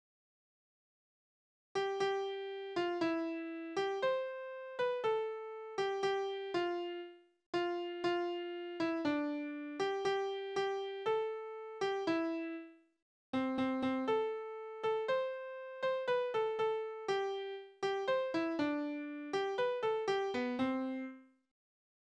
Tonart: C-Dur
Taktart: 4/4
Tonumfang: kleine None
Besetzung: vokal